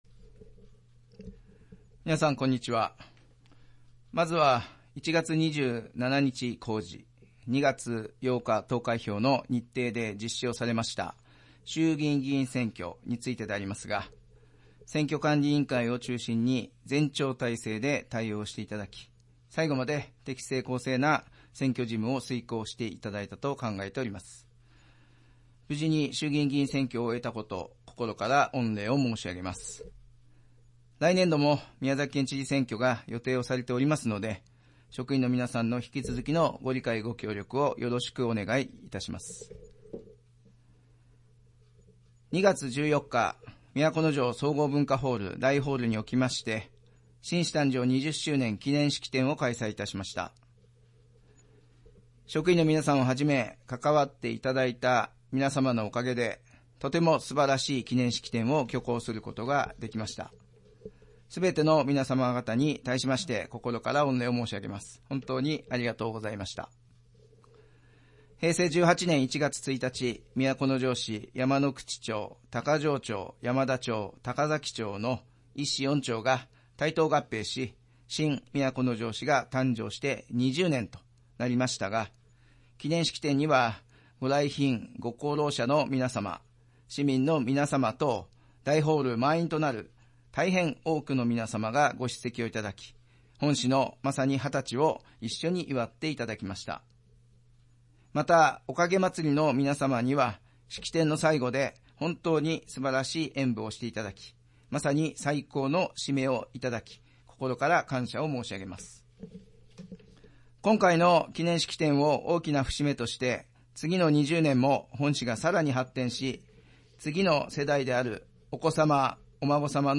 市長が毎月初めに行う職員向けの庁内メッセージを掲載します。
市長のスマイルメッセージの音声